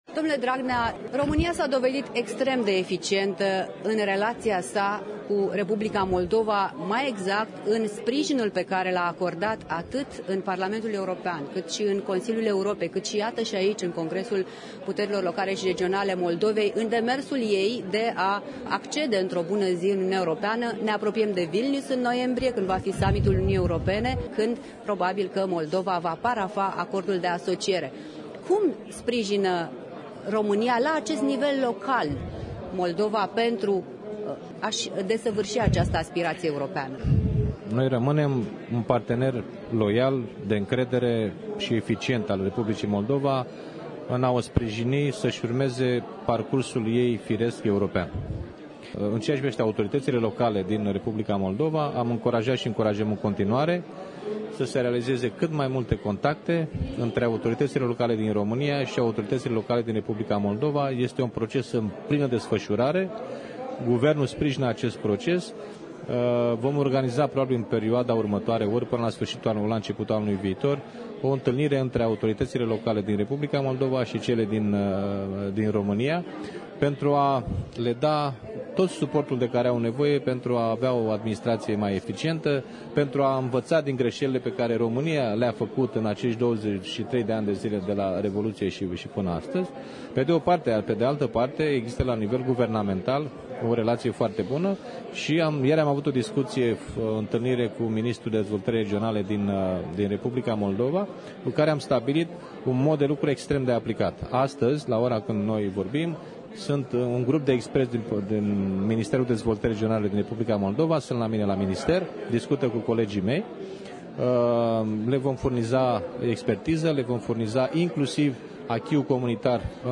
În direct de la Strasbourg